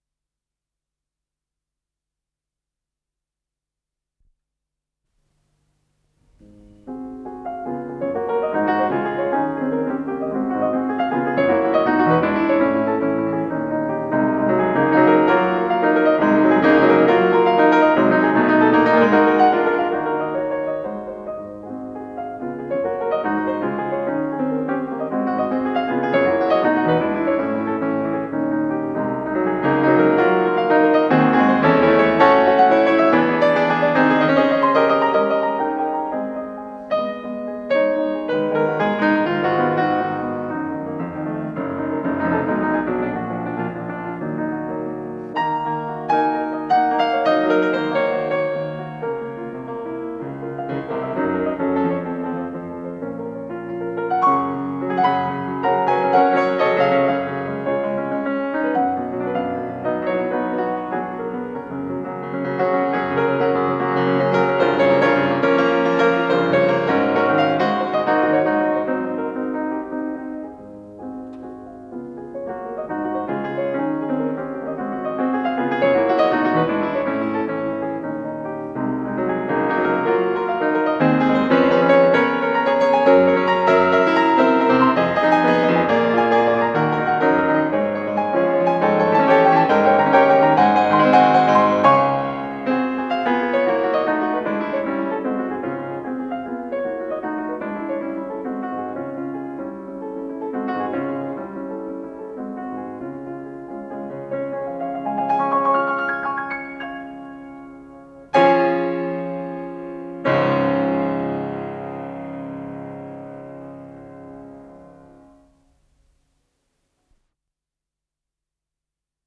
ИсполнителиВиктор Мержанов - фортепиано
Скорость ленты38 см/с
ВариантДубль стерео
Тип лентыORWO Typ 106